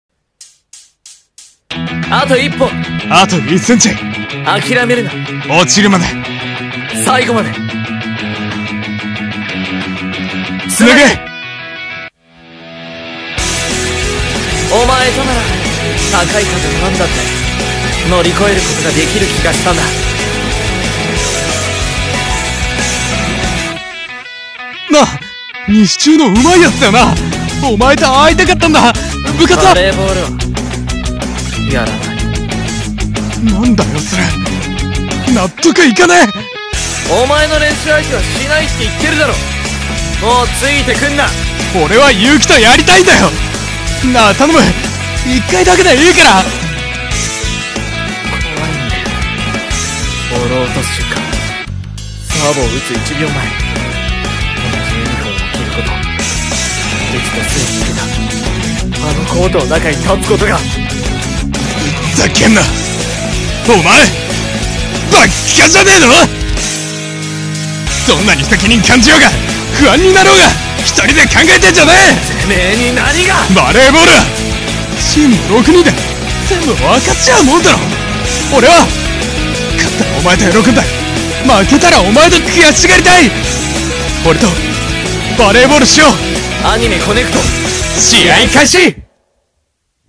【アニメ予告CM風声劇】コネクト【二人声劇】